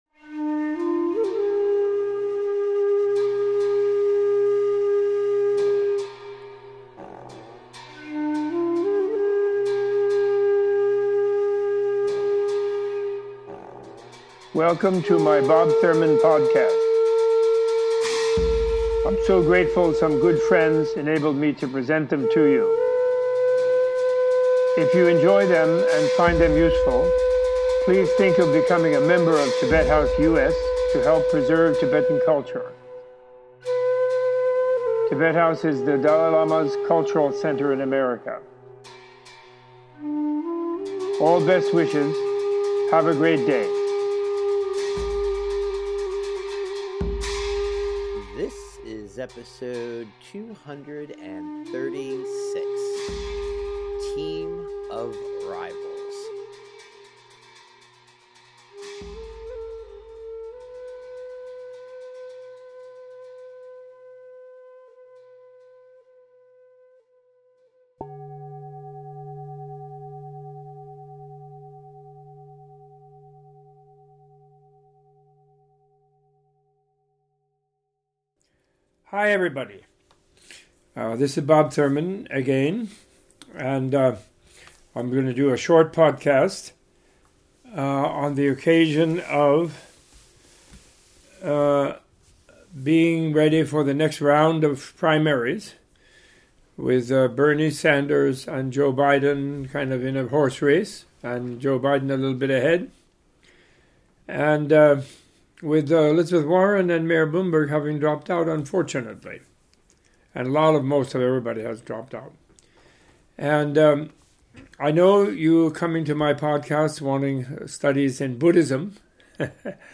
Exploring the landscape of modern democracy as it relates to the election of American presidents through extended primary processes, Robert A.F. Thurman gives a teaching on the need for cooperation between candidates and what a team of rivals would look like.